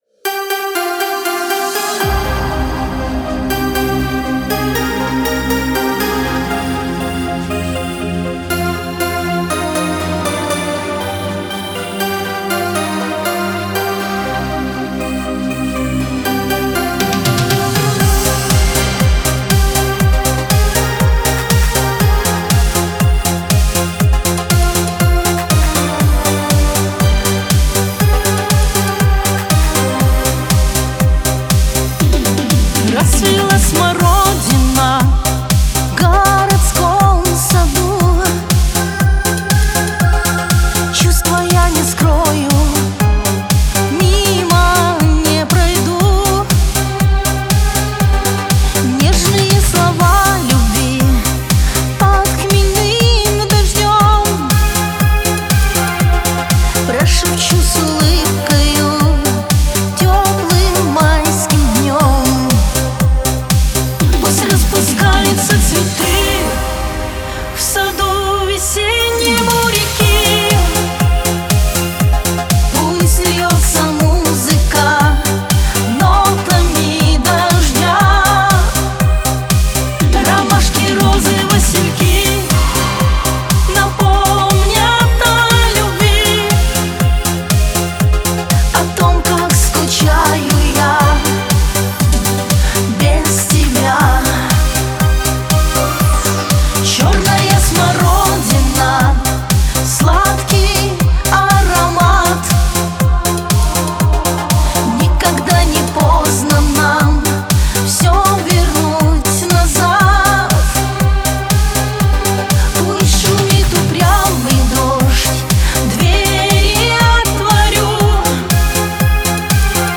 Категория: Шансон песни
Русский шансон